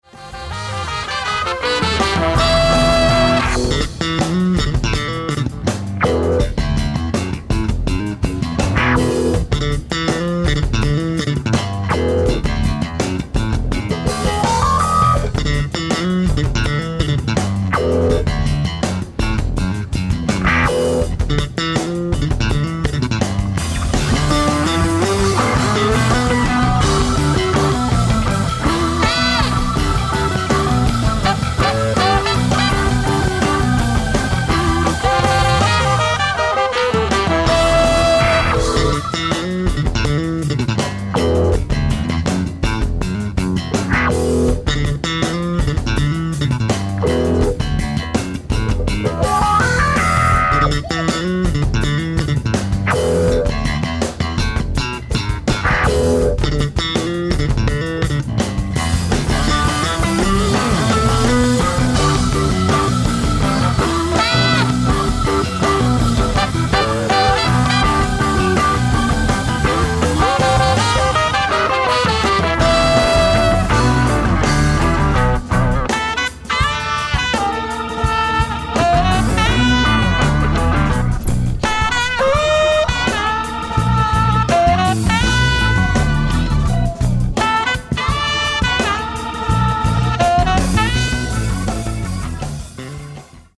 ディスク１：ライブ・アット・キュリージャズ、スイス 04/07/2005
ディスク２；ライブ・アット・ジャズ・マルシアック、フランス 08/03/2005
※試聴用に実際より音質を落としています。